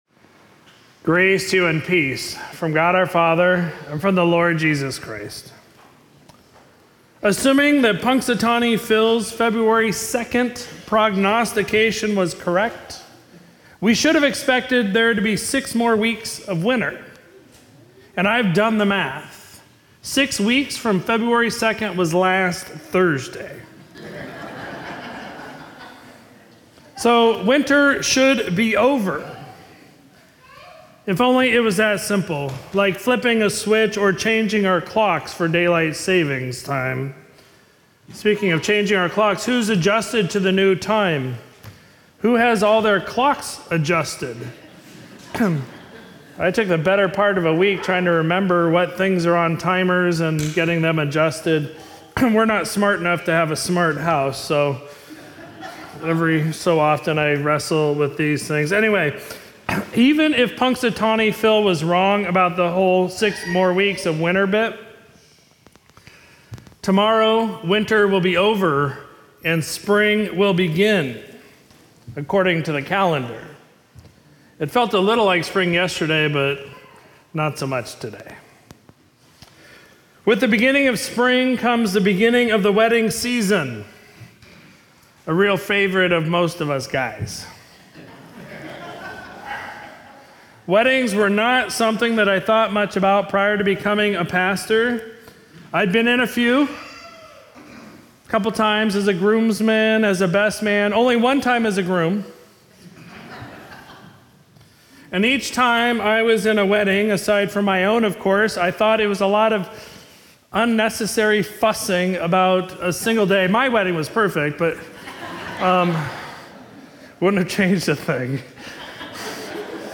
Sermon for Sunday, March 19, 2023